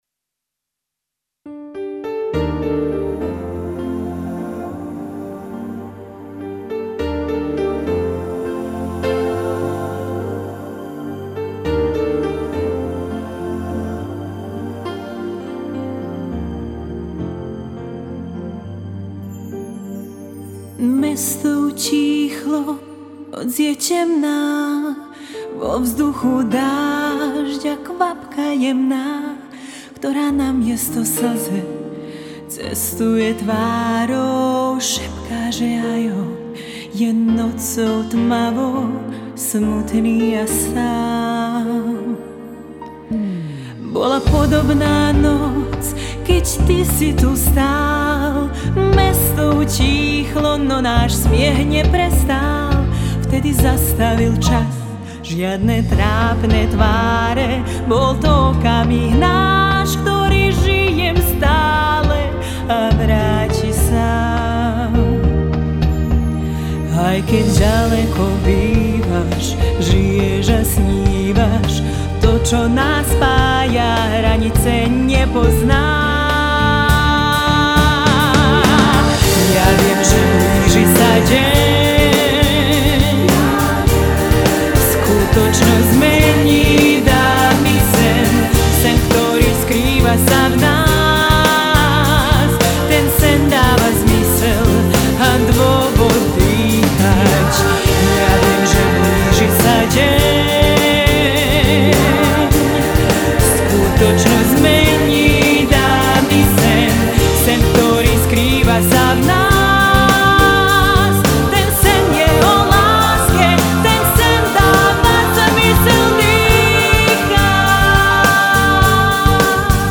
Sólo gitara